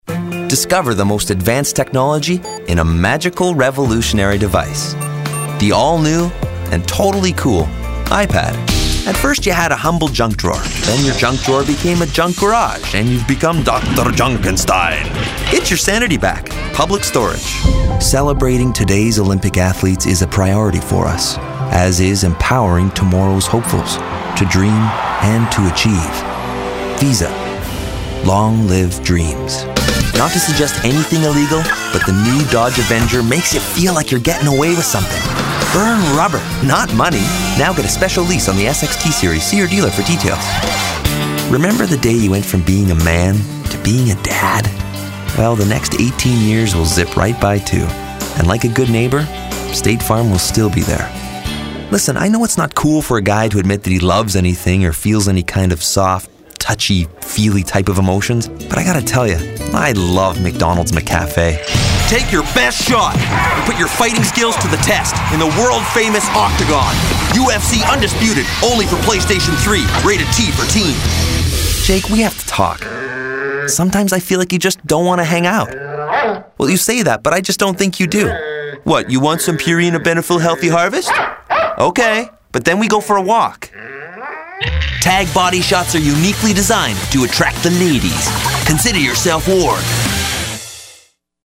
englisch (us)
Sprechprobe: Werbung (Muttersprache):